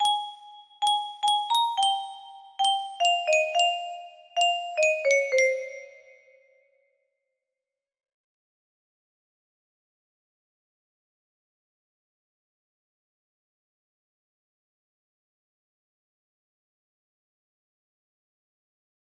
Random riff again music box melody
Full range 60